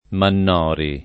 [ mann 0 ri ]